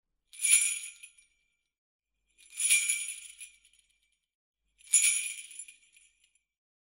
Здесь вы можете слушать онлайн и скачать бесплатно чистые, серебристые перезвоны, которые ассоциируются с Рождеством, зимней сказкой и санями Деда Мороза.
Трижды тряхнули бубенчиками